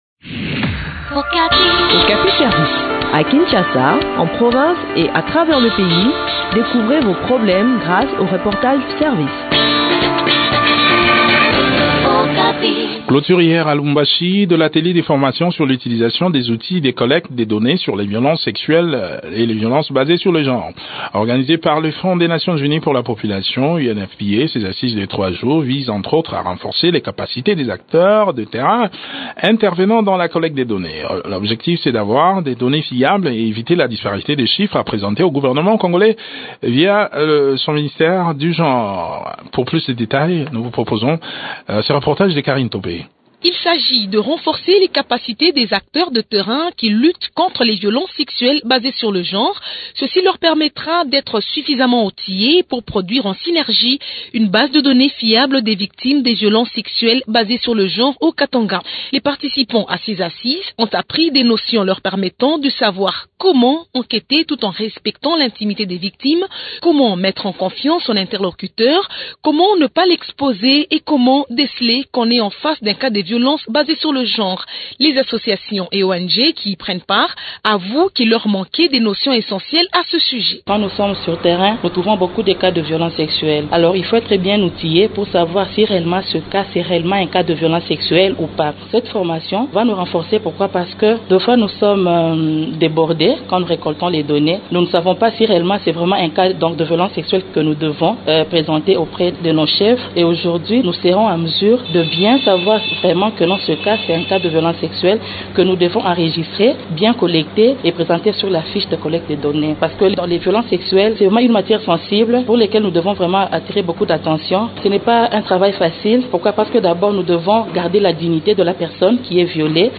Le point sur l’organisation de cette session de formation dans cet entretien